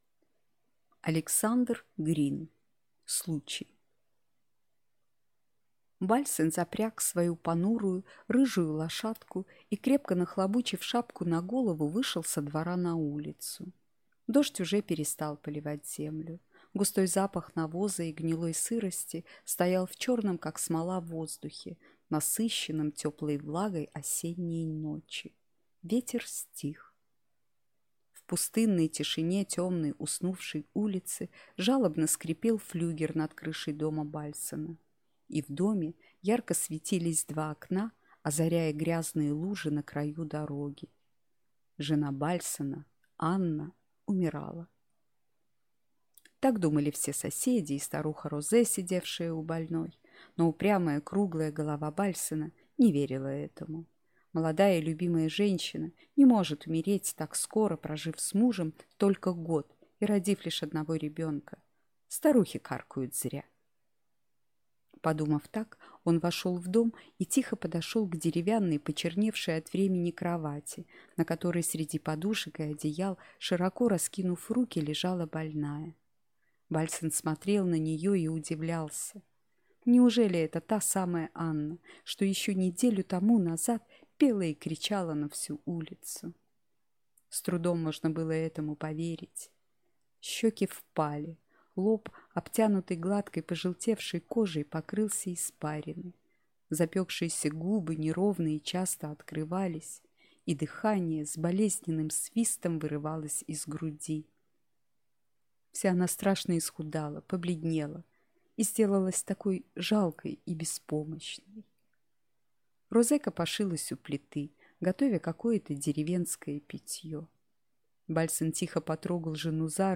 Аудиокнига Случай | Библиотека аудиокниг